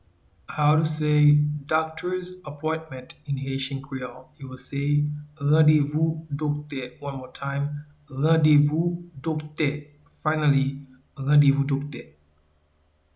Pronunciation and Transcript:
Doctors-appointment-in-Haitian-Creole-Randevou-dokte.mp3